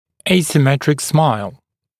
[ˌeɪsɪ’metrɪk smaɪl][ˌэйси’мэтрик смайл]асимметричная улыбка